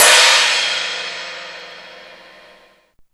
CHINESE01 -L.wav